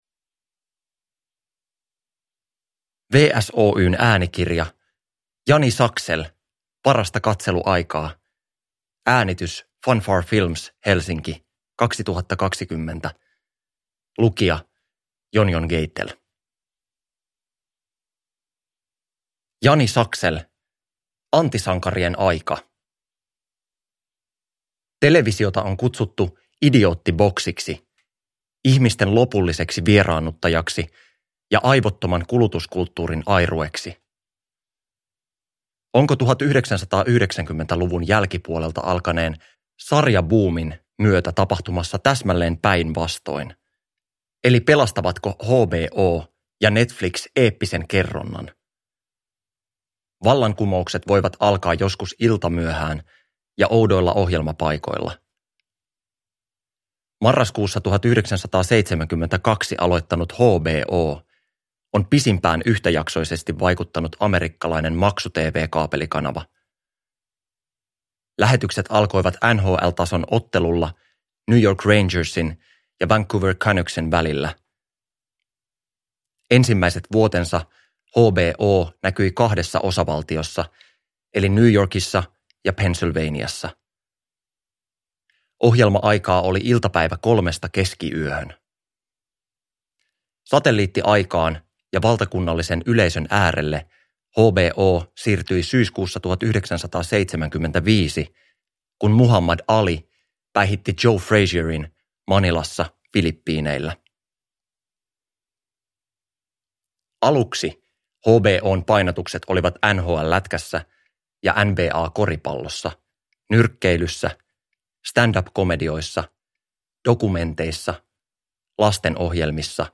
Parasta katseluaikaa – Ljudbok – Laddas ner